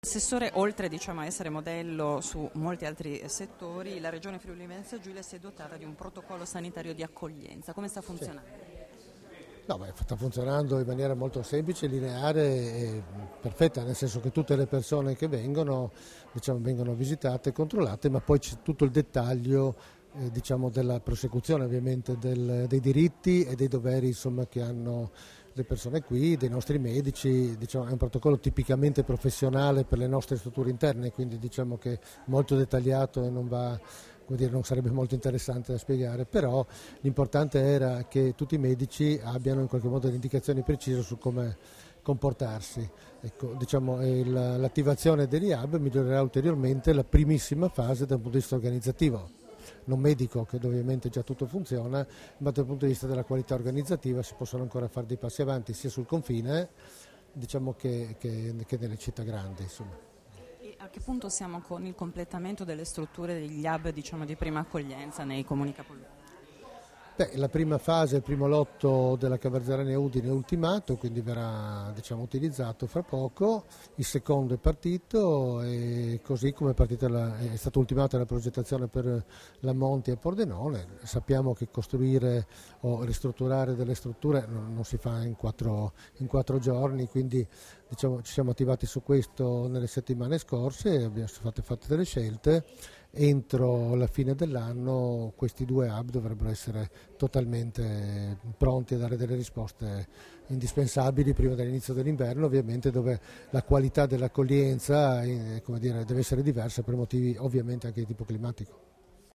Dichiarazioni di Debora Serracchiani (Formato MP3) [2642KB]
alla conferenza stampa di presentazione della fotografia dello stato attuale dell'accoglienza immigrati in Friuli Venezia Giulia, rilasciate a Udine il 2 ottobre 2015